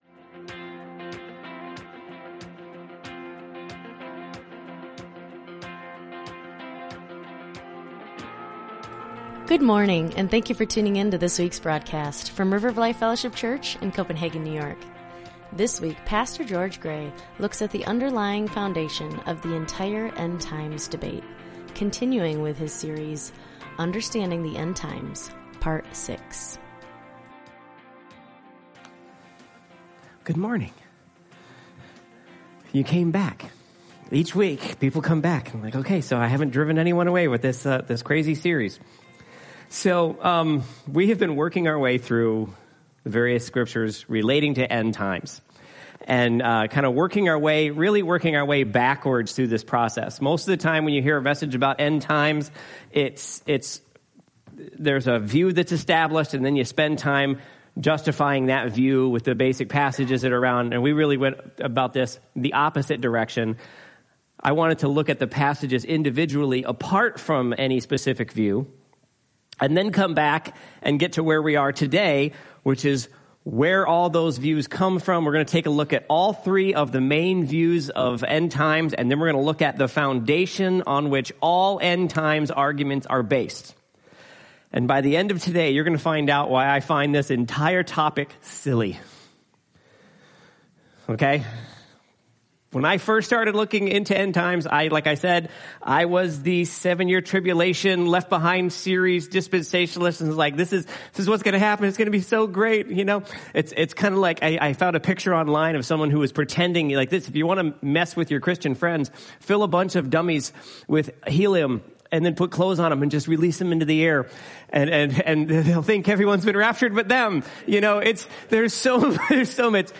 Sermons | River of Life Fellowship Church